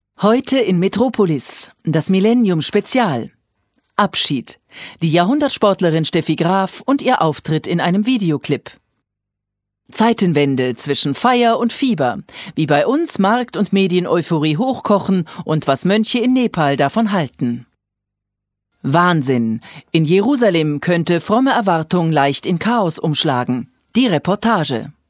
deutsche Sprecherin.
Kein Dialekt
Sprechprobe: Werbung (Muttersprache):
german female voice over artist.